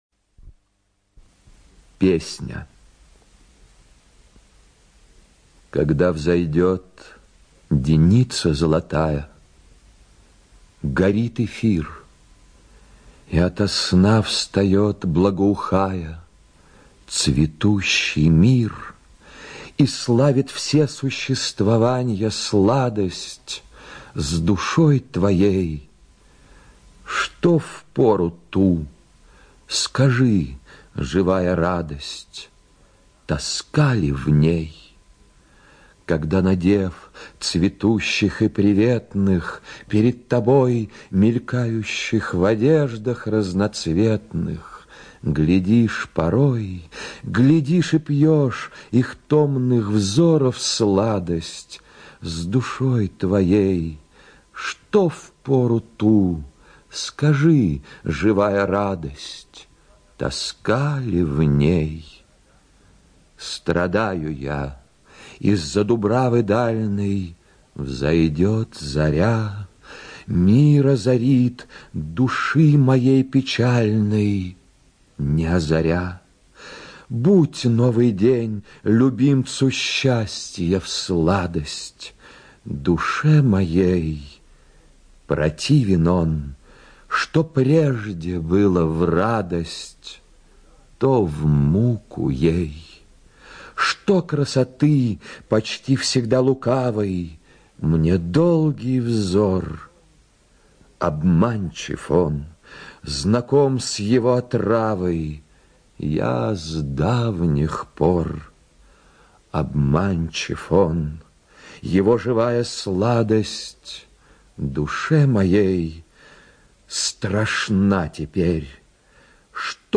ЧитаетБортников Г.
ЖанрПоэзия